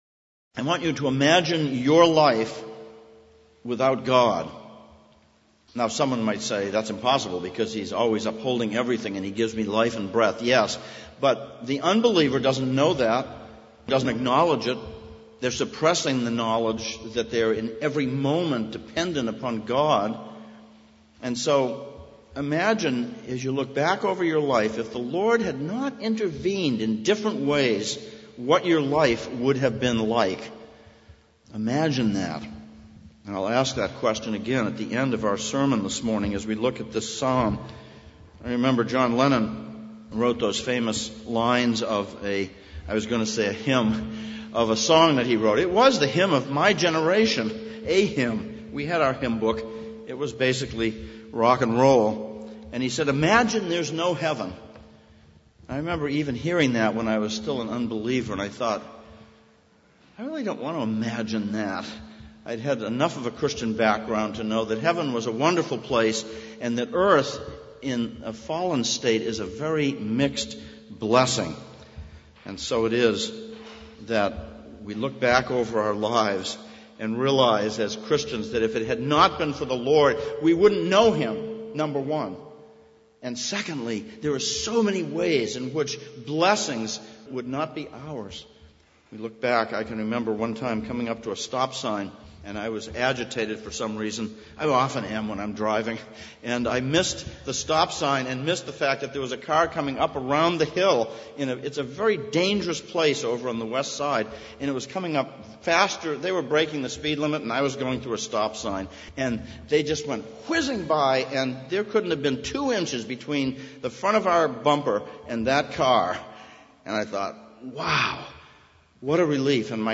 Psalms of Ascents Passage: Psalm 124:1-8, 1 Peter 1:1-9 Service Type: Sunday Morning « 3.